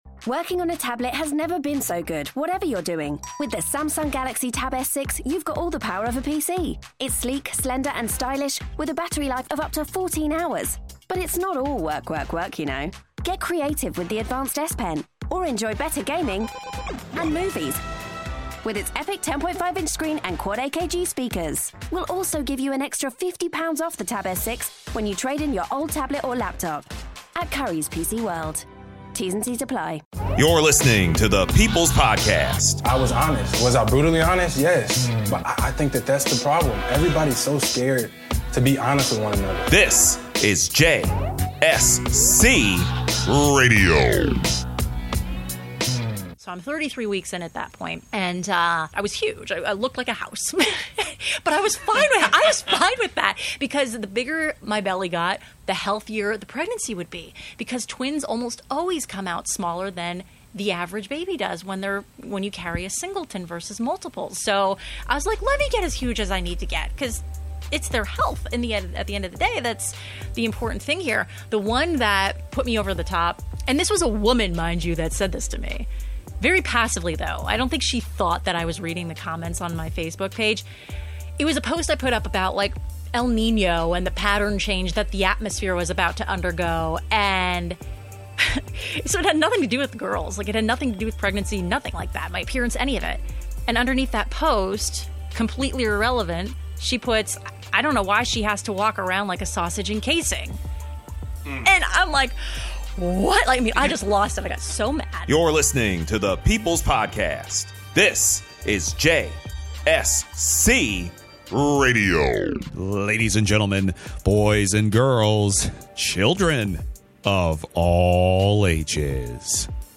(Interview starts at 6:40)